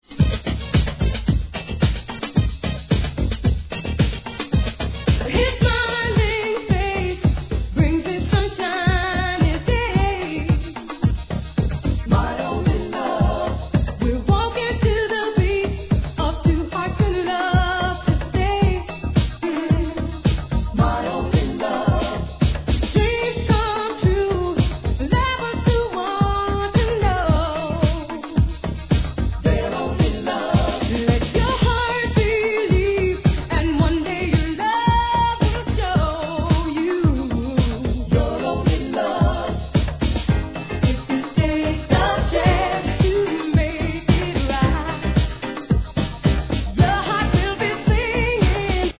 1. HIP HOP/R&B
N.J.S. classic!!